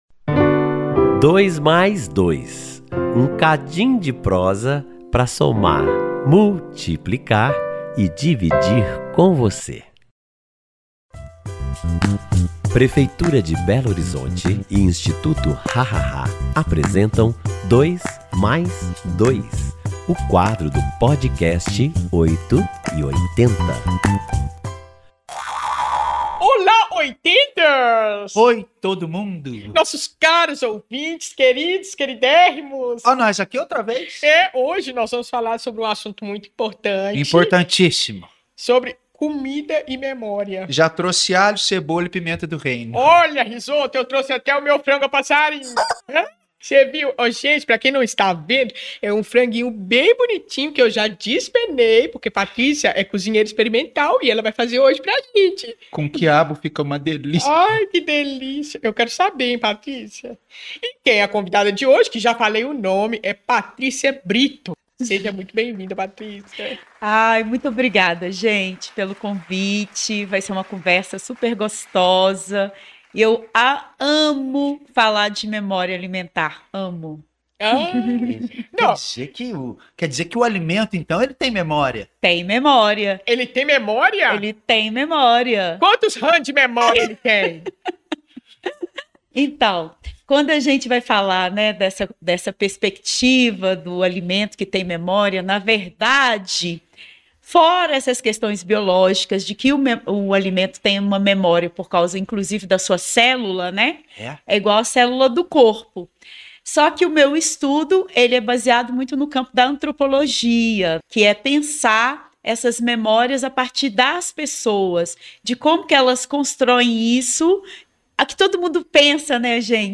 conversam com a cozinheira experimental